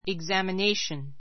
examination iɡzæmənéiʃən イ グ ザミ ネ イション